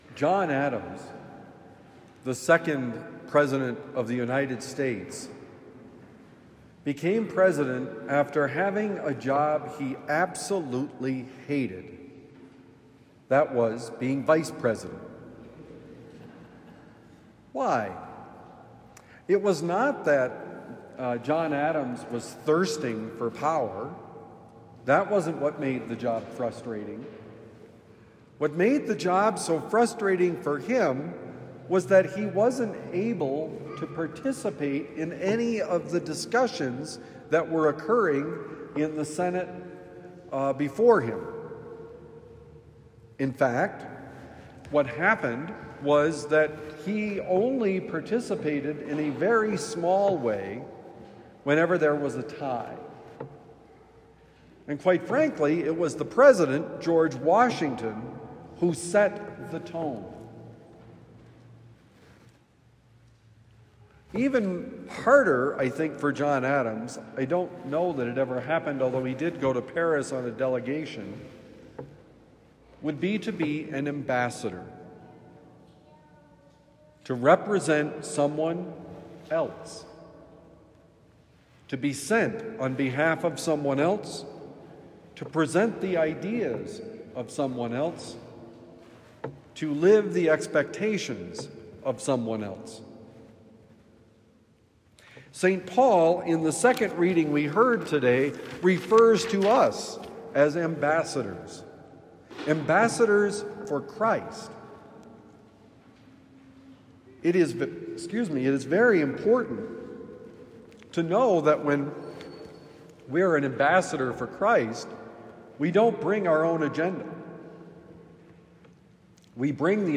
An Ambassador for Christ: Homily for Ash Wednesday, 2026 – The Friar